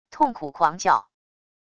痛苦狂叫wav音频